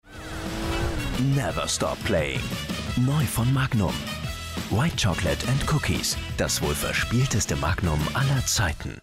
Erfahrener deutscher Sprecher.
Meine Stimme kann warm, beruhigend, bestimmt, dynamisch oder energetisch klingen, so wie Sie es brauchen.
Sprechprobe: Werbung (Muttersprache):
My voice can sound warm, calming, determined, dynamic or energetic as you need it to be.